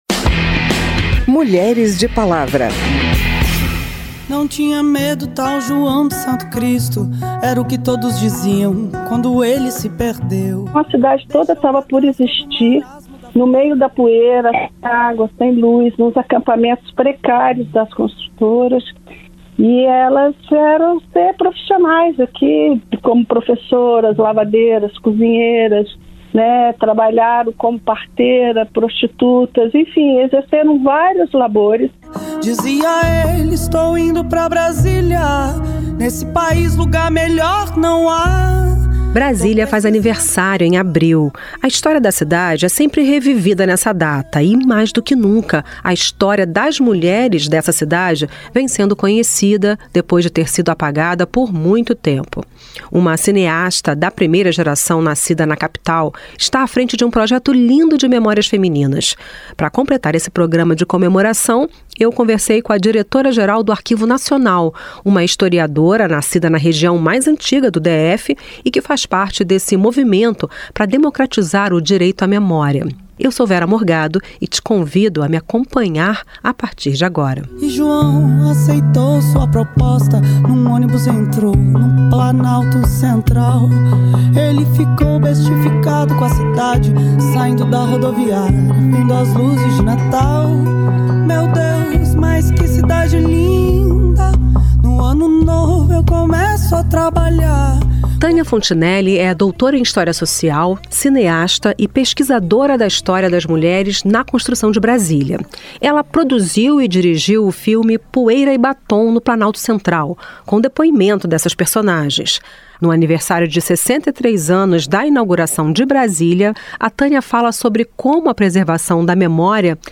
E tem ainda uma entrevista com a nova diretora do Arquivo Nacional, que nasceu na região mais antiga do Distrito Federal e trabalha para democratizar o direito à memória.